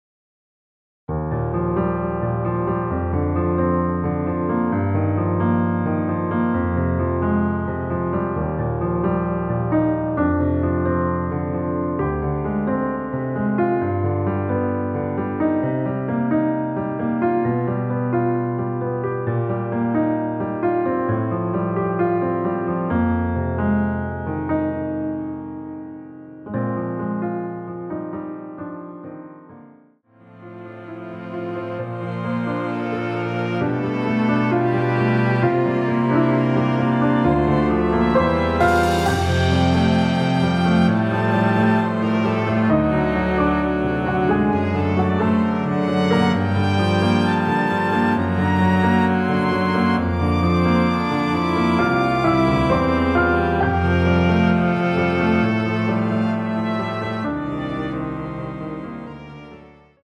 원키에서(-1)내린 MR입니다.
Eb
앞부분30초, 뒷부분30초씩 편집해서 올려 드리고 있습니다.
중간에 음이 끈어지고 다시 나오는 이유는